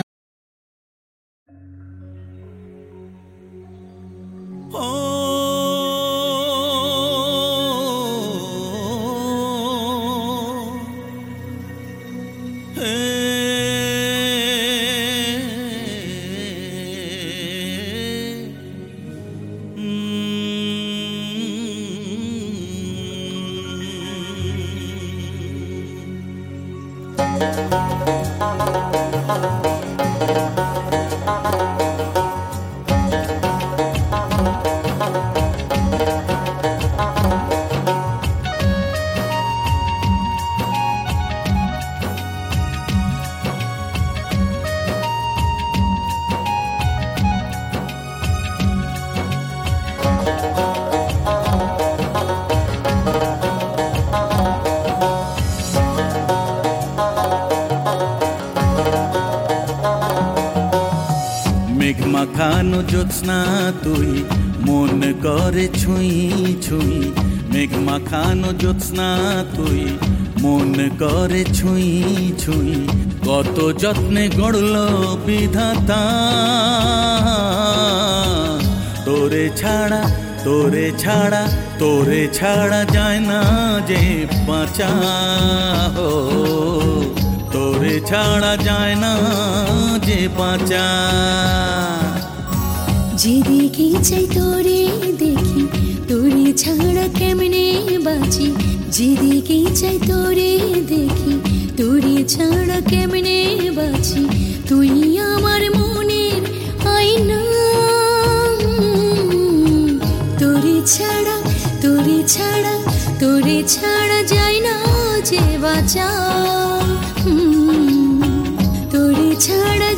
Bengali